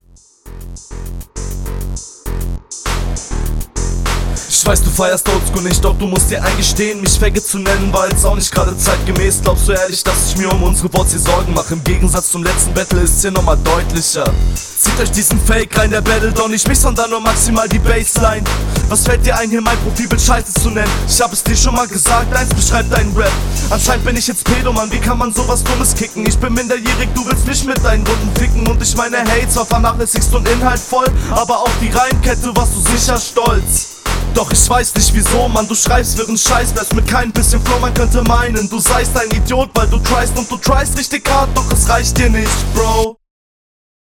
Battle Runden
"deutlicher" gut, auch schön arrogant betont.